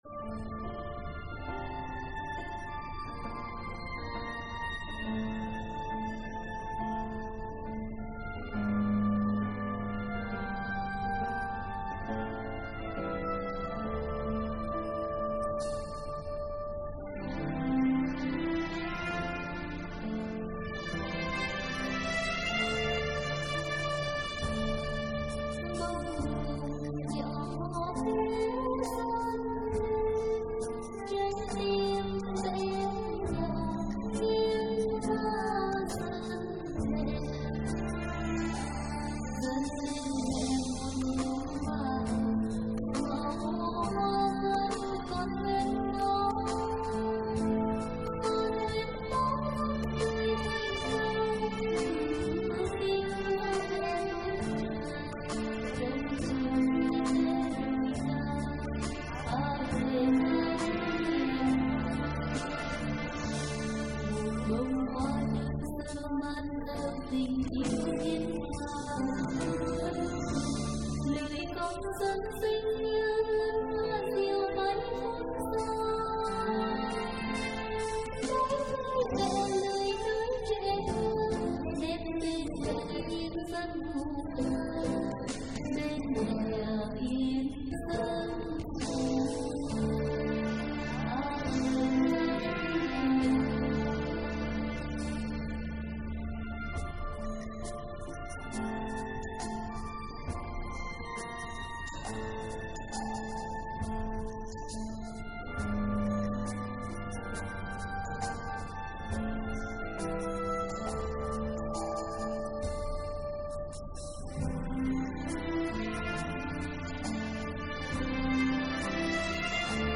thể hiện hơn 90 bài hát Thánh Ca.